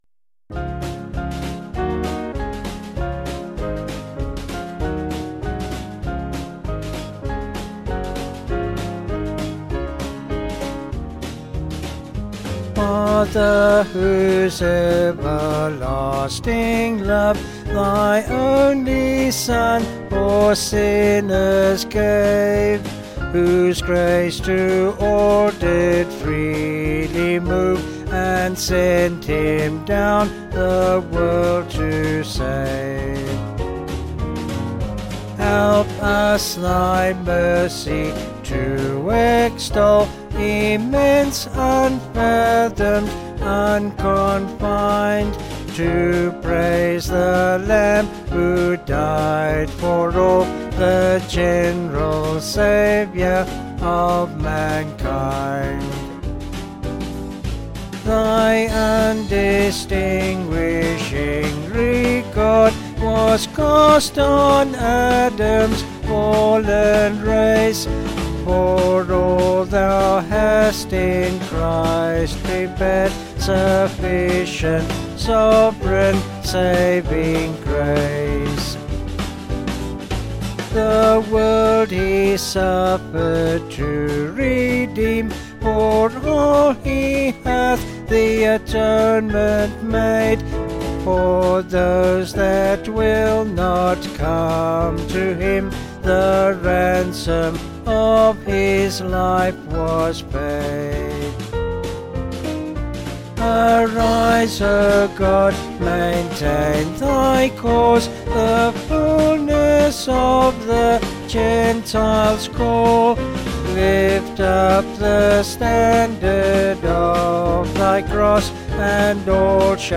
(BH)   5/Db-D-Eb
Vocals and Band   264kb Sung Lyrics